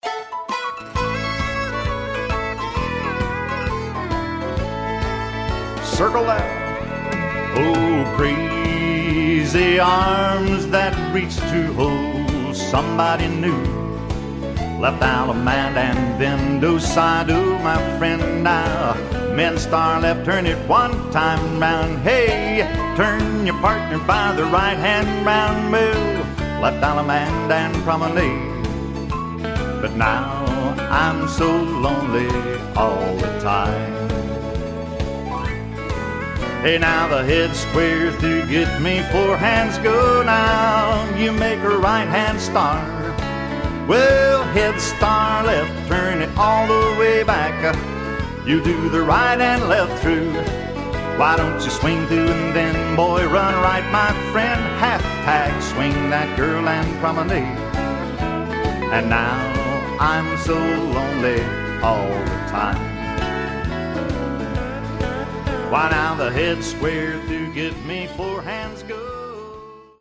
Square Dance Music